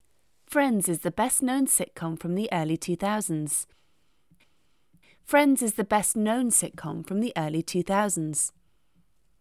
Does the emphasis go on ‘best’ or ‘known’? Let’s have a listen:
It’s ever-so subtle, but can throw off a narrator very easily, and as a result can throw off your audiences too.